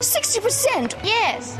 RP’s DRESS vowel [e̞] was also closer than its contemporary value, as demonstrated by sixty percent and yes from the ad: